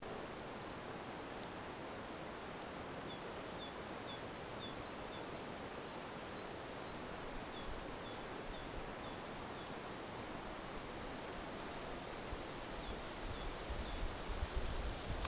Database dei canti ed altre vocalizzazioni
Salve a tutti, non so se è il posto giusto ma vorrei un parere per il riconoscimento di questo uccello canoro (forse due diversi).
Volevo specificare che la registrazione è stata fatta a circa 300 m slm in un uliveto nel Valdarno tra Firenze ed Arezzo oggi 22 febbraio in una bellissima giornata di tiepido sole !!!